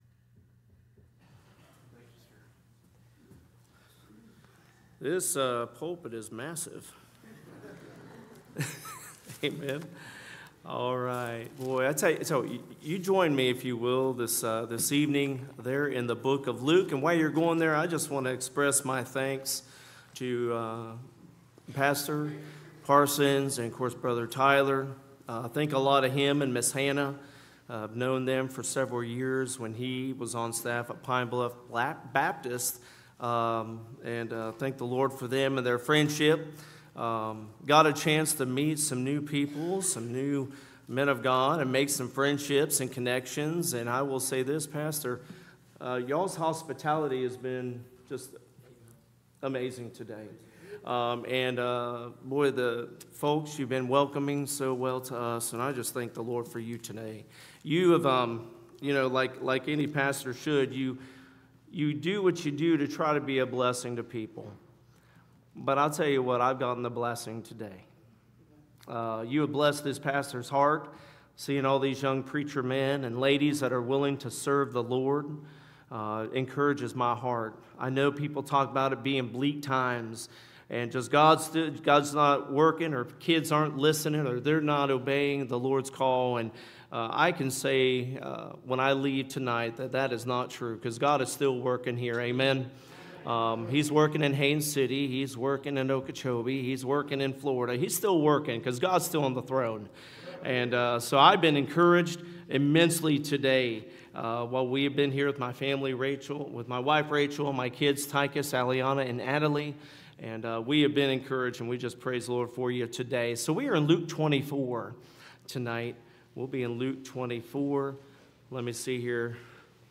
Series: Back To School Revival 2025